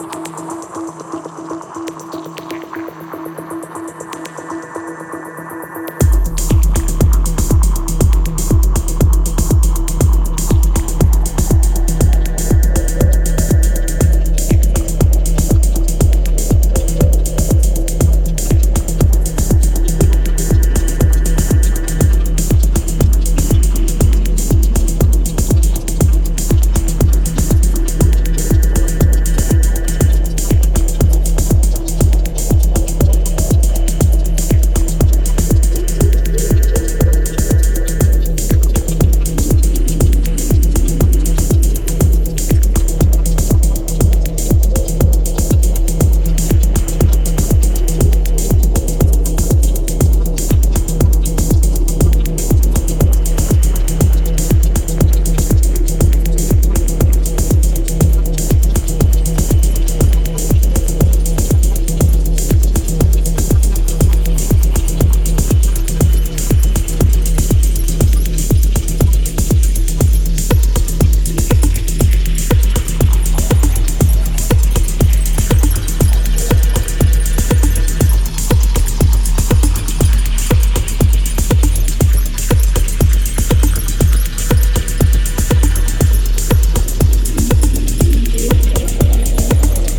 重心低いドープなグルーヴの求心力が並々ならぬ120BPMのディープ・ミニマル傑作。